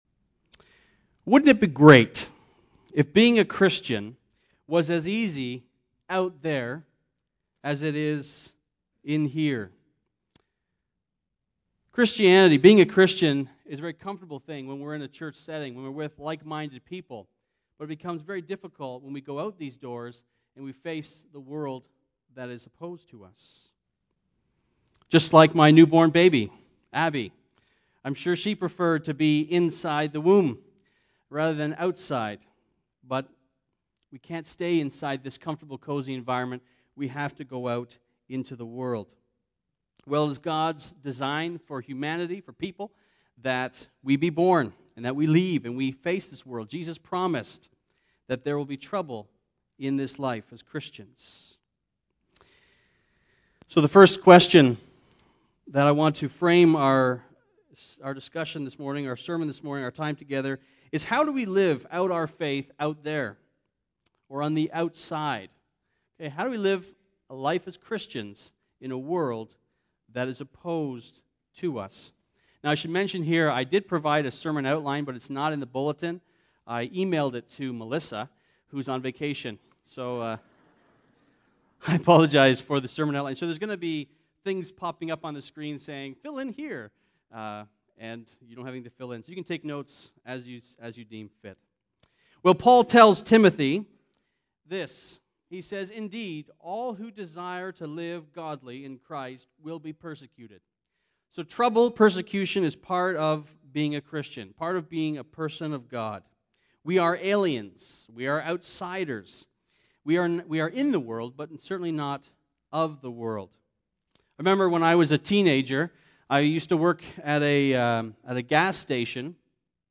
Sermon Archives Jul 27